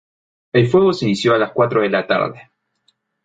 fue‧go
Pronúnciase como (IPA)
/ˈfweɡo/